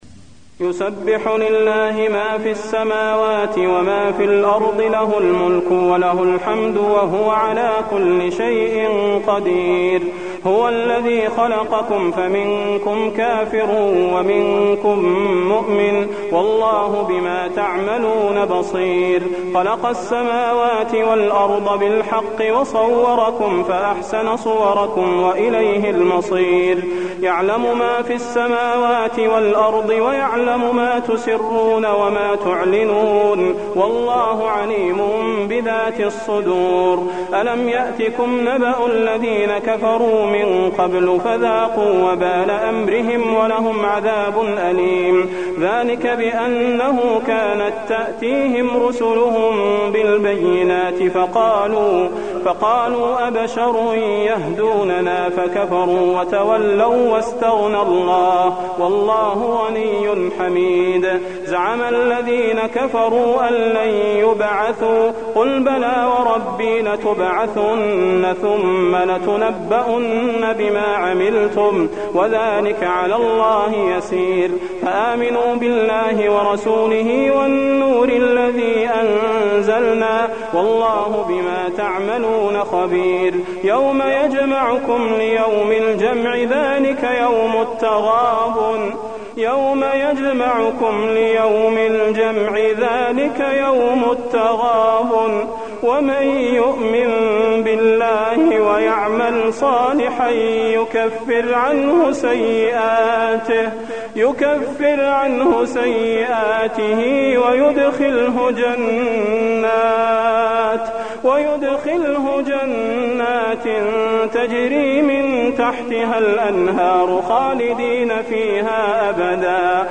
المكان: المسجد النبوي التغابن